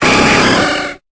Cri de Poissoroy dans Pokémon Épée et Bouclier.